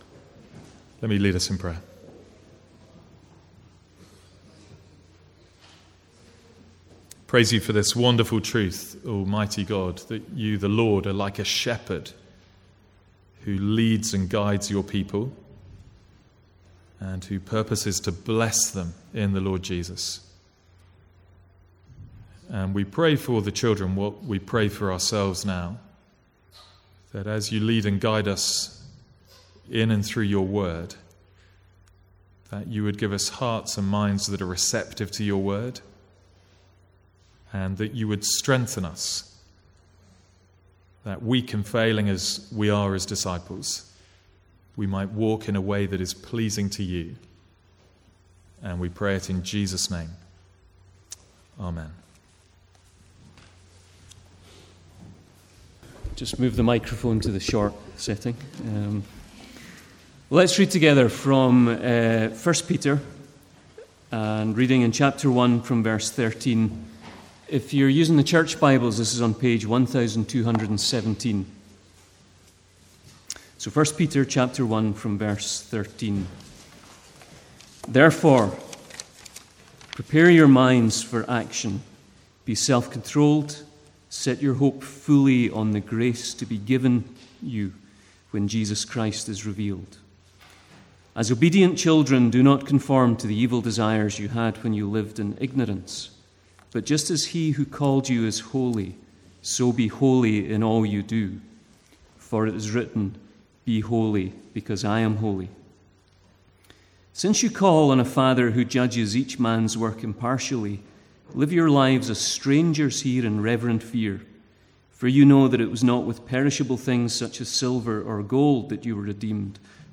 Sermons | St Andrews Free Church
From the Sunday morning series in 1 Peter.